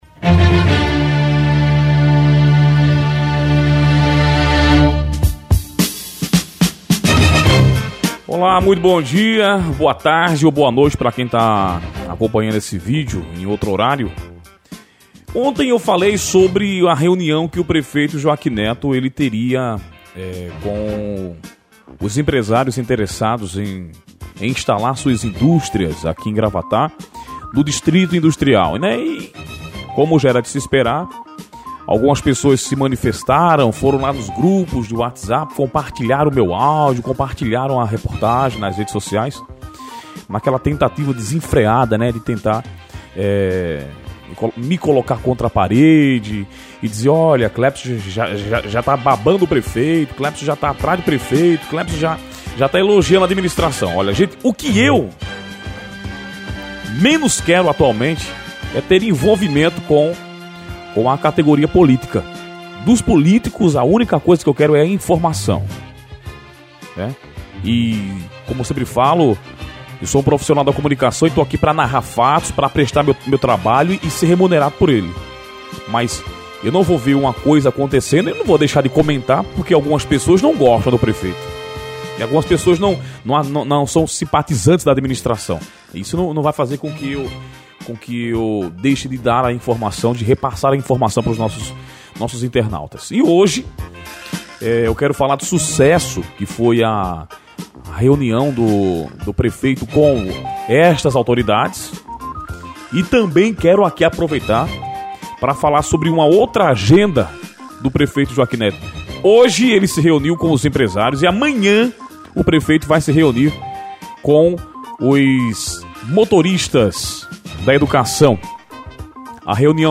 COMENTÁRIO-MOTORISTA-GRAVATÁ.mp3